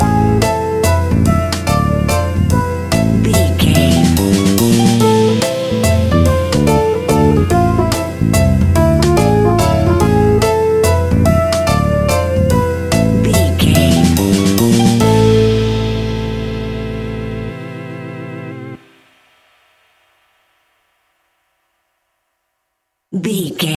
Aeolian/Minor
scary
ominous
haunting
eerie
playful
electric piano
drums
bass guitar
synthesiser
spooky
horror music